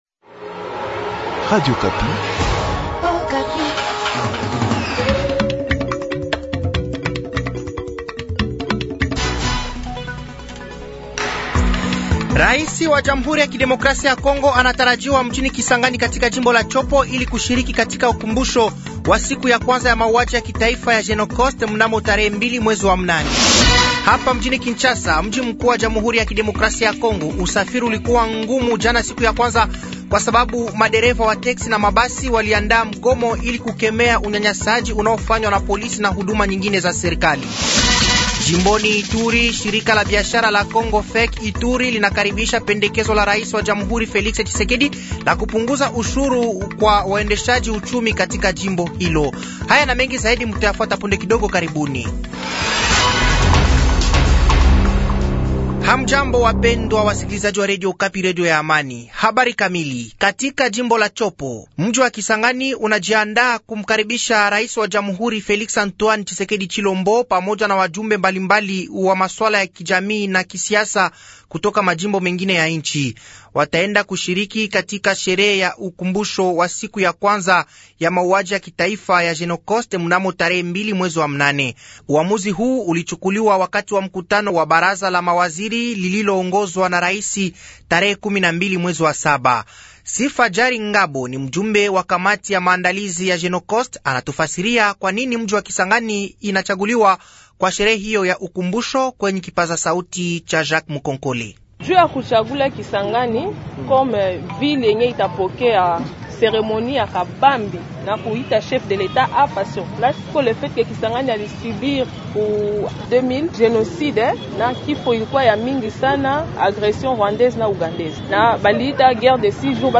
Journal matin
Habari za siku ya pili asubuhi tarehe 30/07/2024